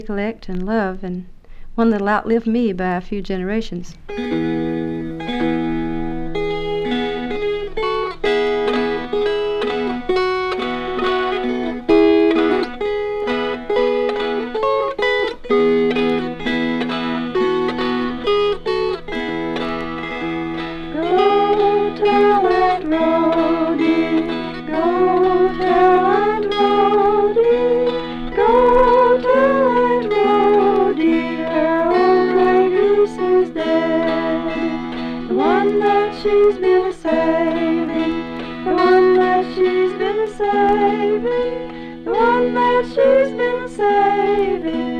Folk, World, Apparachian Music　USA　12inchレコード　33rpm　Stereo